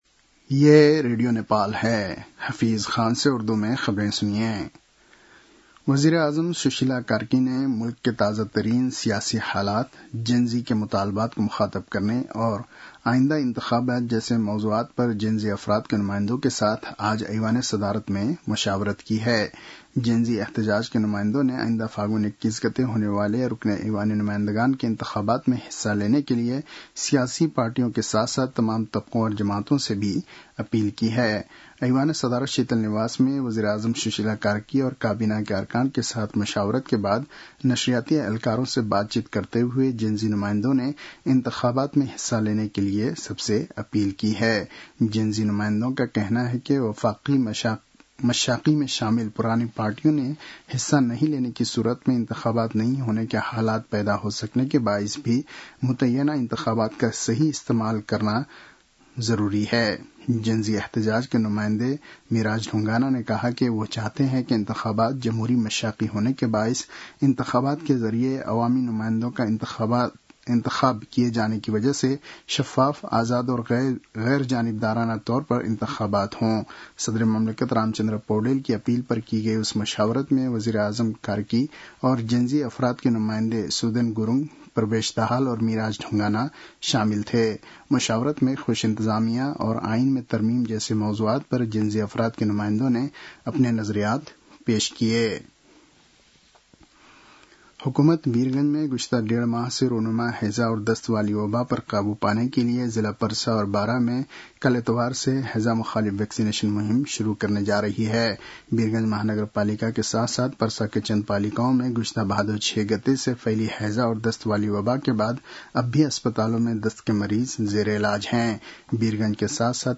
उर्दु भाषामा समाचार : २५ असोज , २०८२